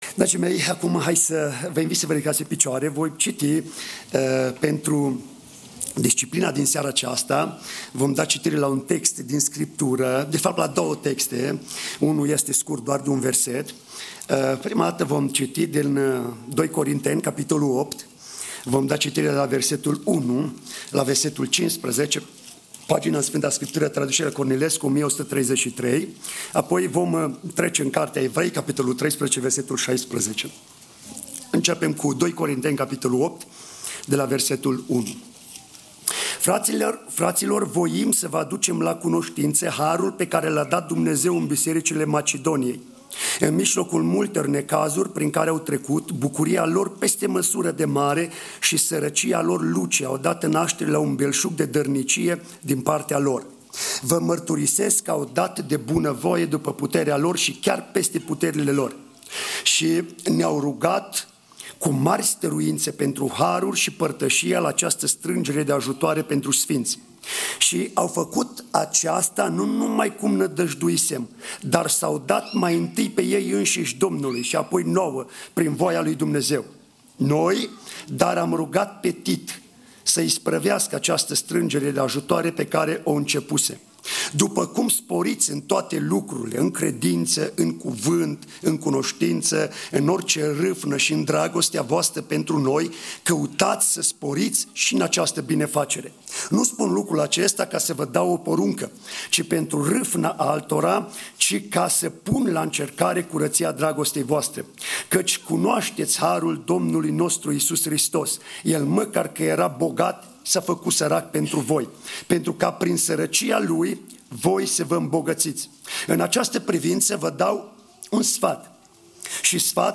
Series: Disciplinele Spirituale Passage: 2Corinteni 8:1-15 | Evrei 13:16 Tipul Slujbei: Slujba Seara